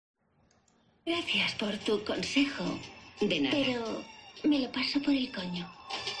Simplemente rescato este trozo en MP3 de Kim Kardashian contestándole a su madre de una manera sorprendente. El doblaje que hacen en español de «Las Kardashian» a veces me parece un poco chocante, aunque me gusta que se tomen ciertas licencias.